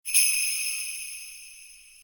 Sleigh bells